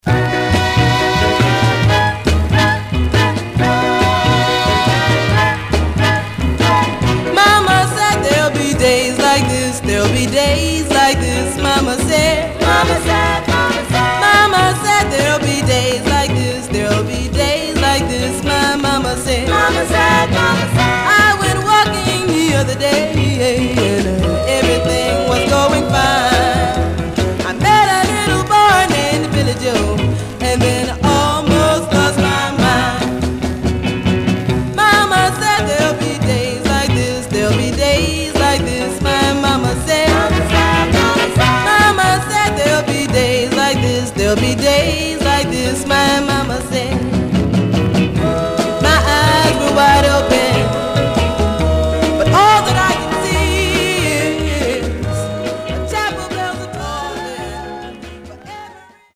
Some surface noise/wear Stereo/mono Mono
Black Female Group